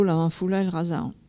collecte de locutions vernaculaires
Catégorie Locution